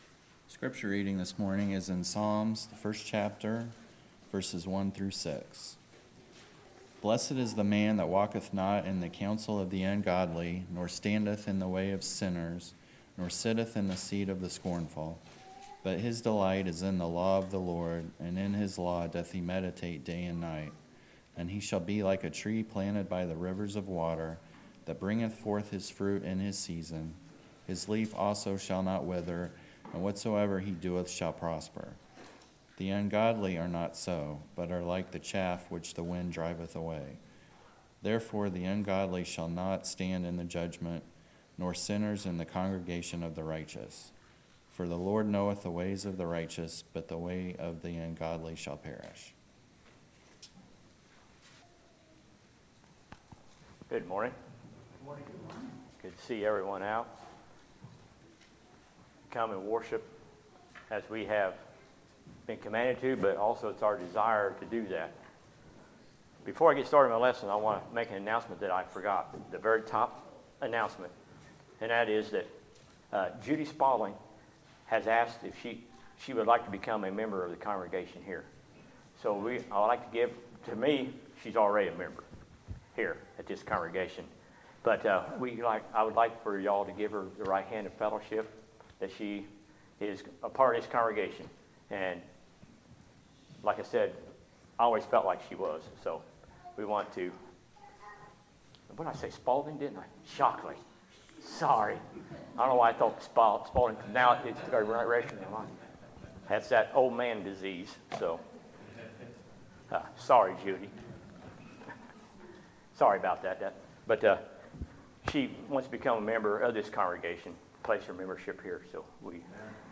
Sermons, July 21, 2019